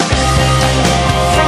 alert.wav